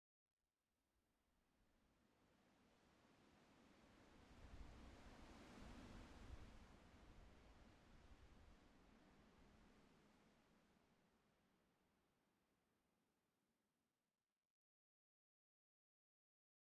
minecraft / sounds / block / sand / wind6.ogg
wind6.ogg